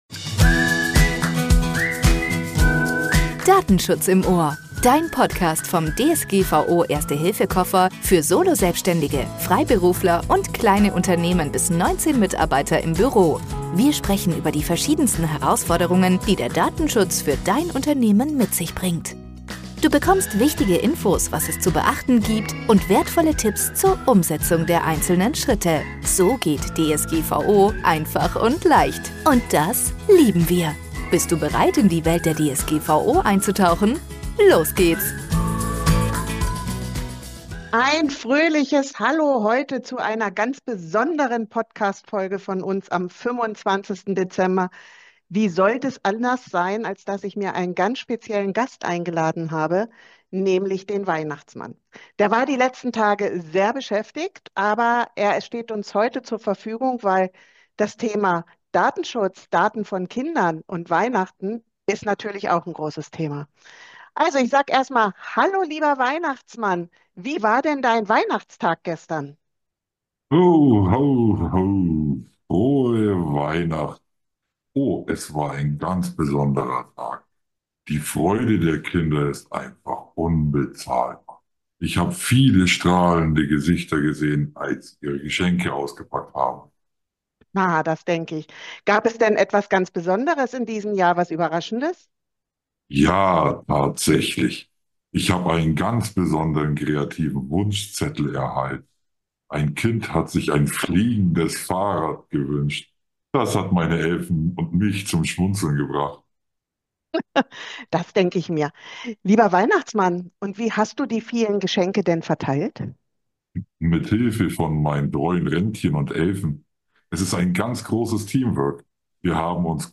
#26 Datenschutz am Nordpol - Interview mit dem Weihnachtsmann ~ Datenschutz im OHR - DSGVO Erste Hilfe für Solo-Selbstständige und Kleinunternehmen leicht gemacht Podcast